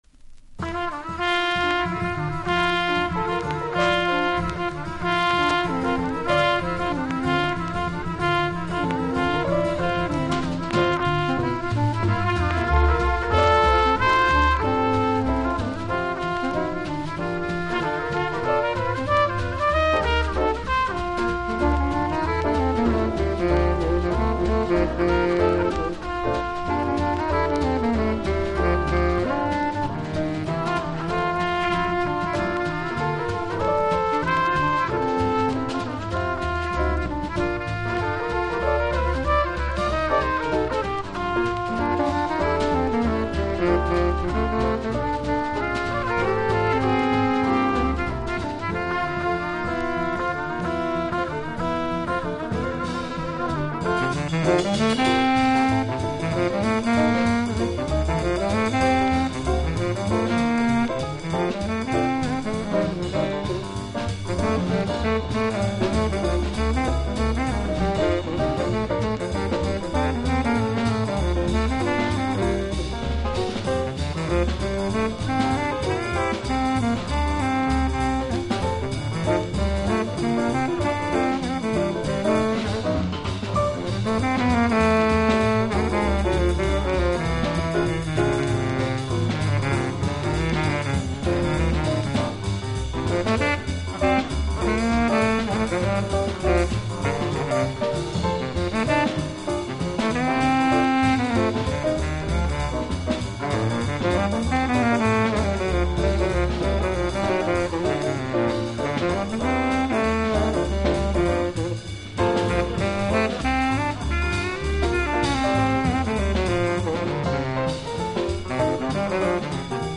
（小傷によりチリ、プチ音ある曲あり）
Genre US JAZZ